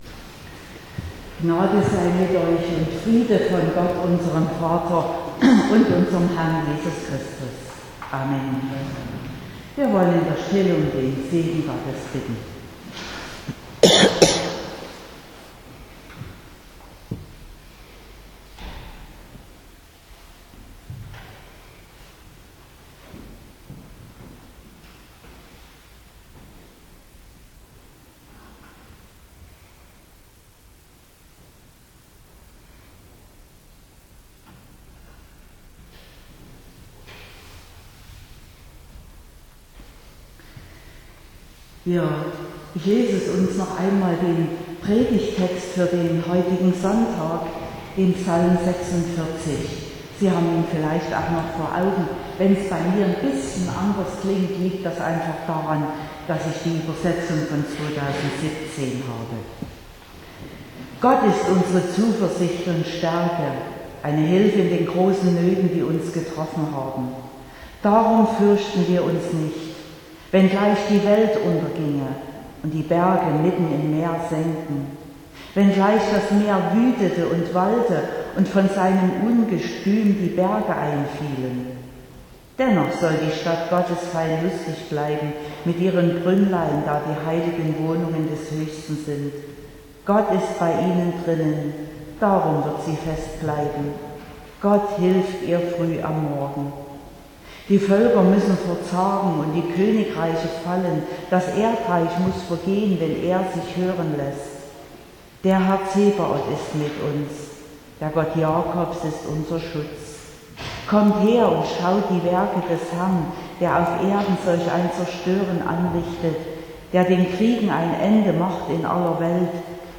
31.10.2022 – Gottesdienst
Predigt (Audio): 2022-10-31_Womit_fuellen_wir_unser_Herz_.mp3 (24,9 MB)